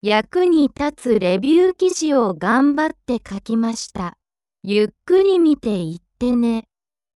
先ほどの音声をすごくゆっくり喋らせてみるとこんな感じ
リカ女性10.mp3